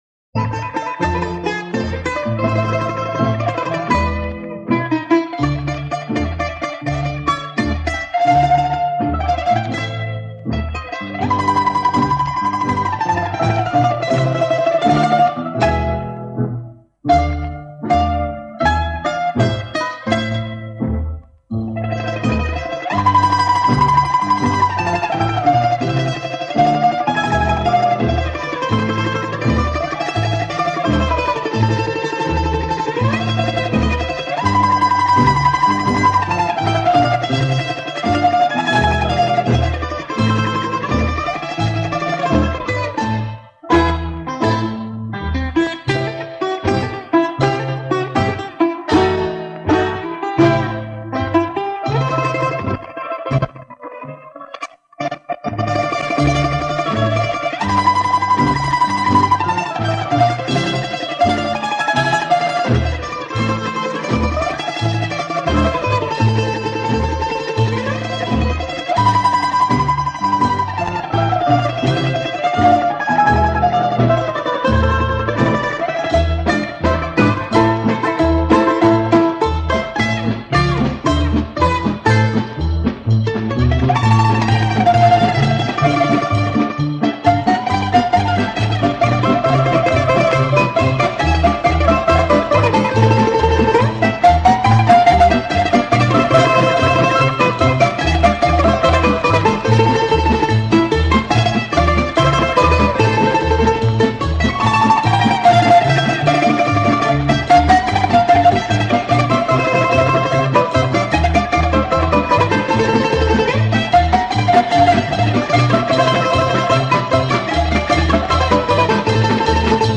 Στο πλαίσιο του αφιερώματος του Δεύτερου Προγράμματος για τον Βασίλη Τσιτσάνη ακούμε ένα μοναδικό ντοκουμέντο. Ήταν φθινόπωρο του 1973 και ο Γιώργος Παπαστεφάνου φιλοξενεί τον Τσιτσάνη στην τηλεοπτική εκπομπή «Η μουσική γράφει ιστορία». Πρόκειται για ένα σπάνιο ντοκουμέντο με τον Τσιτσάνη και τη Μπέλλου μαζί.